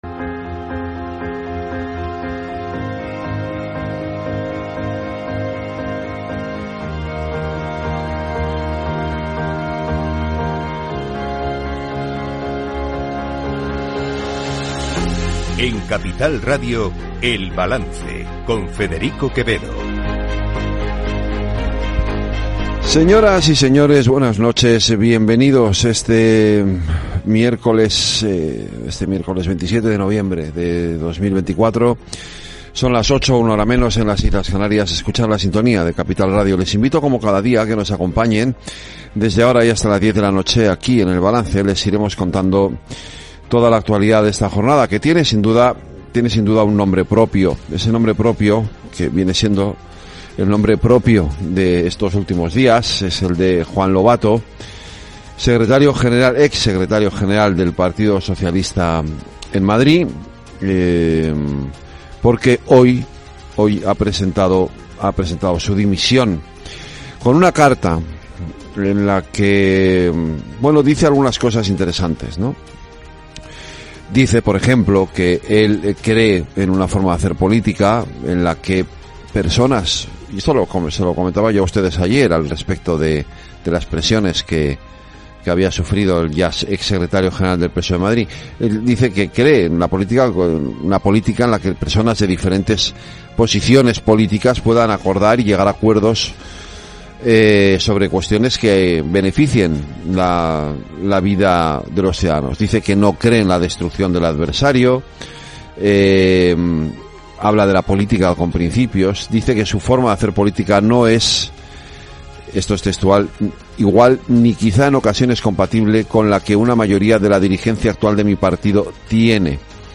El Balance es el programa informativo nocturno de Capital Radio, una manera distinta, sosegada y reflexiva de analizar la actualidad política y económica